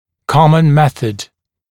[‘kɔmən ‘meθəd][‘комэн ‘мэсэд]общепринятый метод